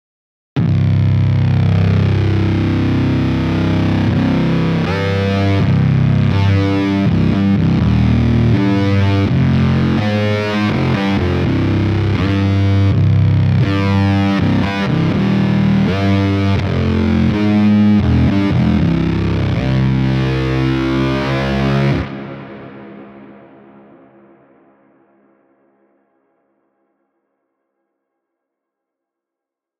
Cone_Buster_Synth.mp3